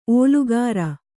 ♪ ōlugāra